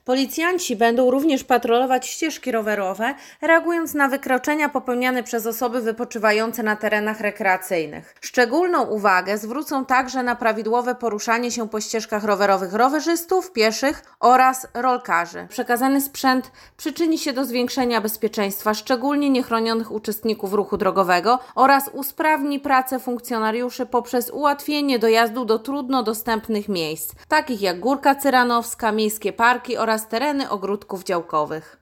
| Radio Leliwa